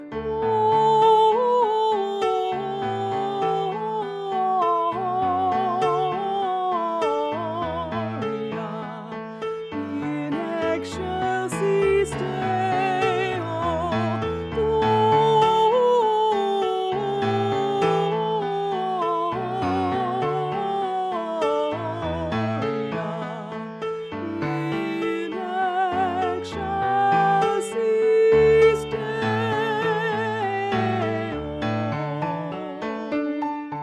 choral song arrangements